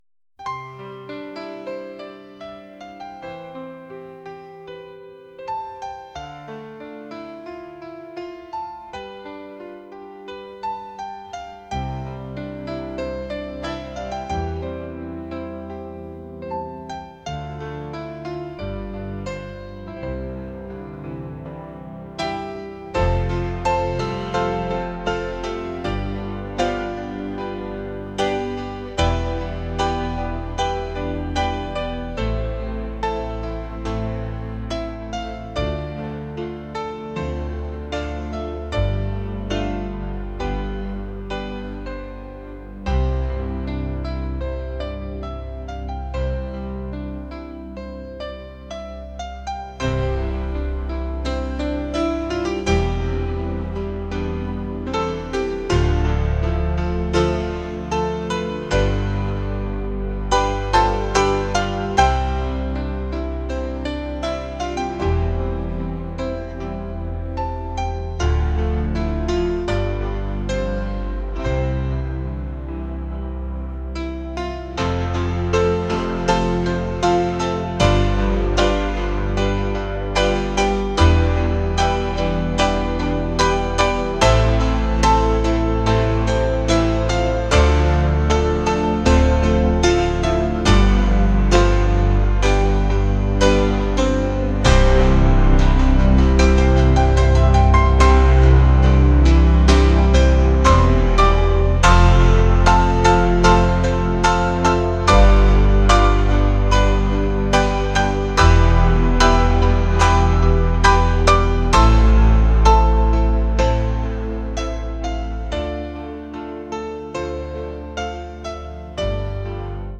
pop | acoustic | romantic